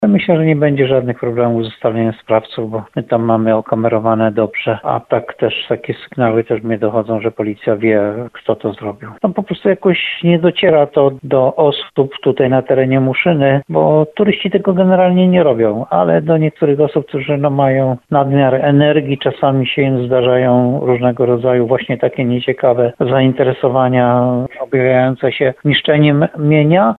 Jak mówi burmistrz Muszyny Jan Golba, sprawa ma zostać skierowana na policję.